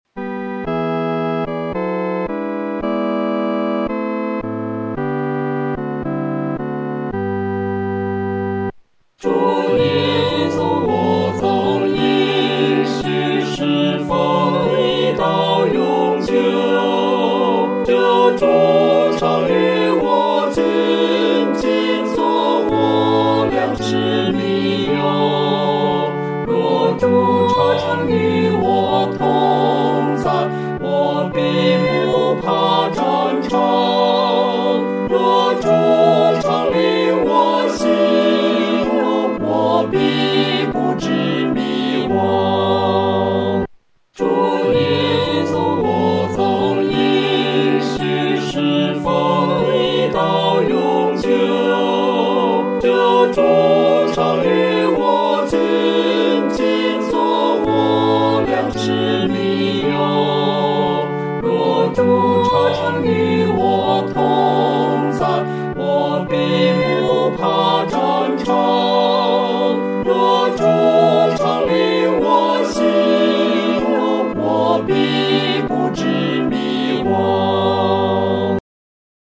合唱
四声